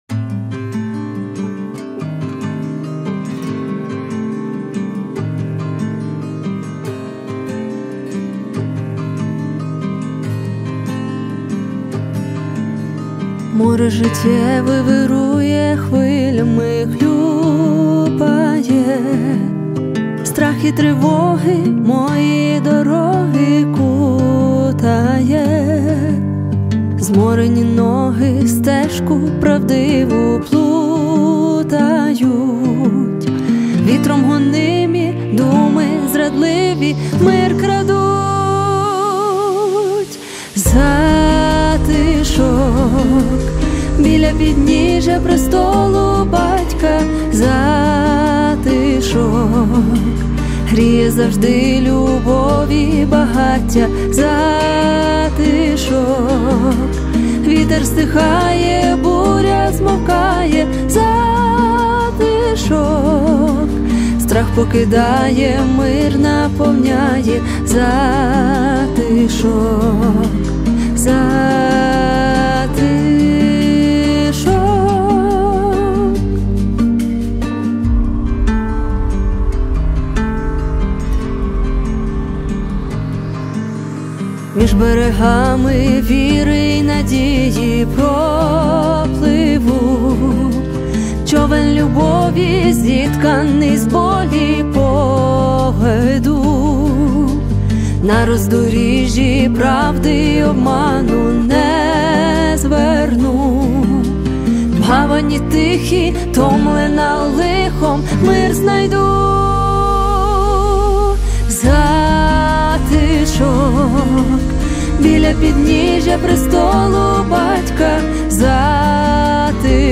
352 просмотра 271 прослушиваний 45 скачиваний BPM: 143